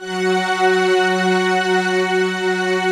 SI1 CHIME0BR.wav